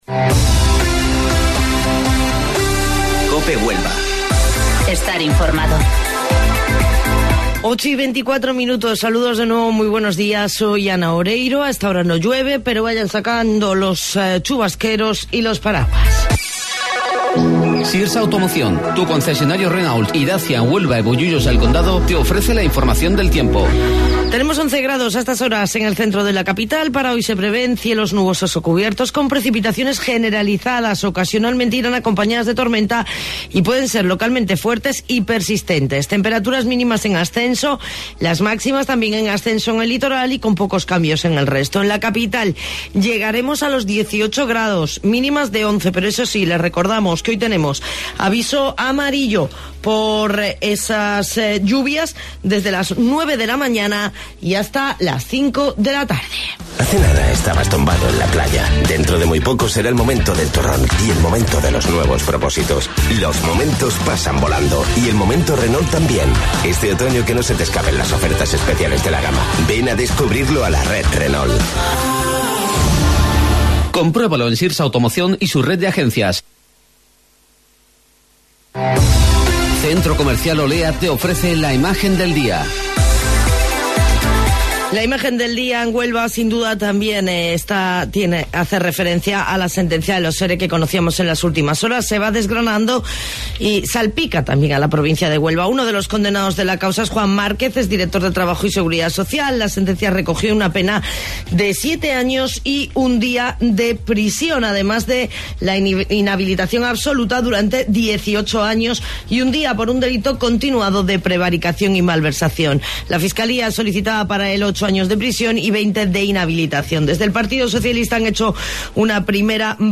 AUDIO: Informativo Local 08:25 del 20 de Noviembre